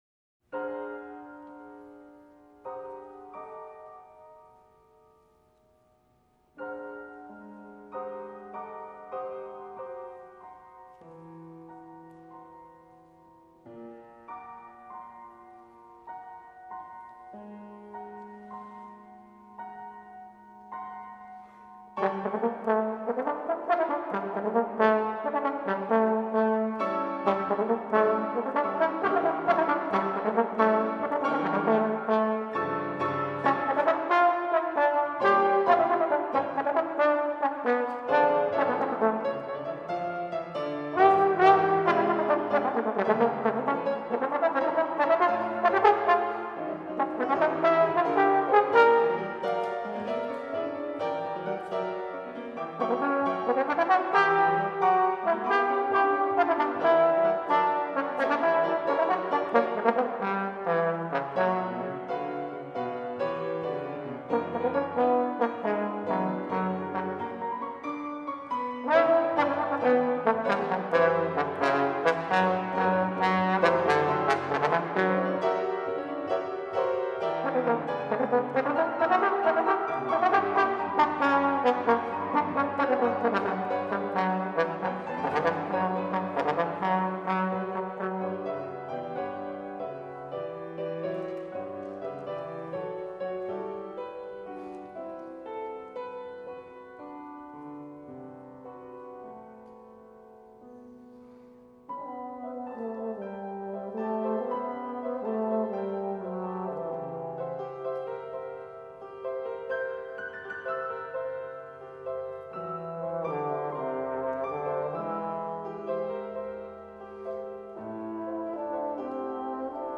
fiendishly virtuosic concert piece
trombone
piano